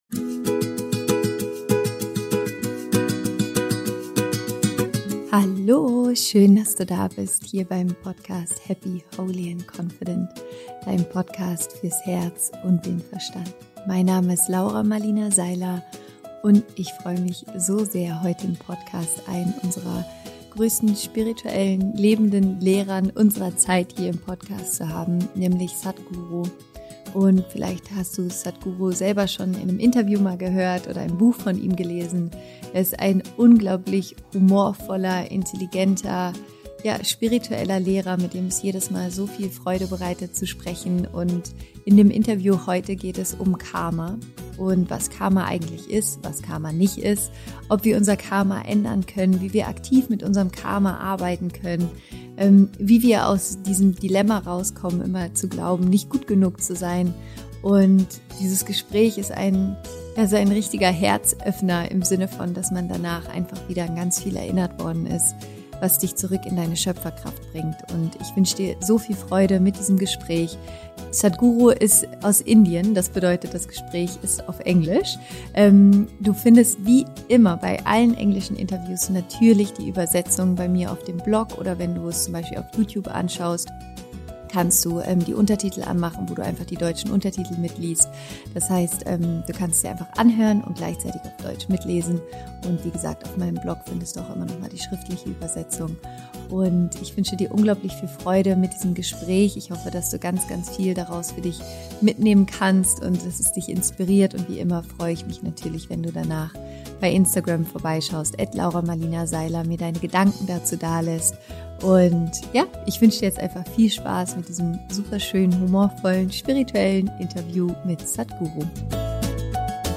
Wie du dein Karma änderst - Interview Special mit Sadhguru